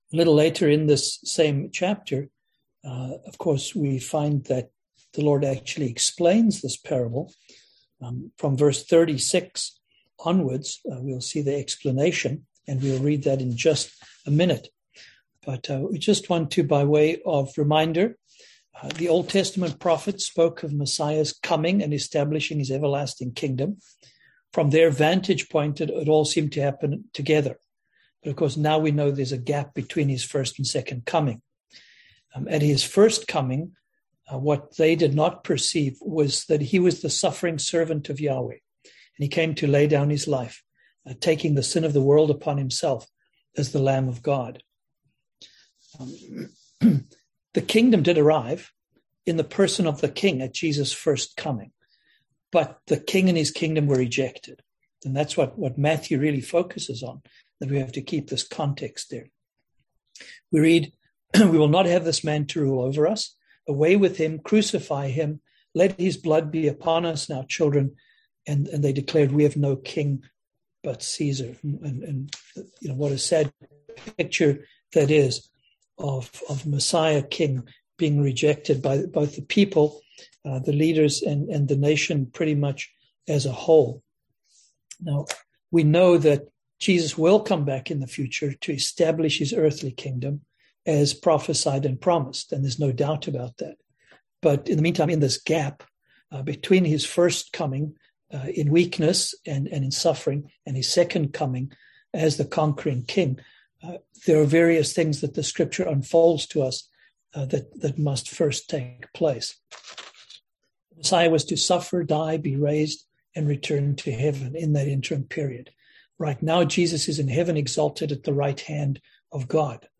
Series: Kingdom Parables 2021 Passage: Matthew 13 Service Type: Seminar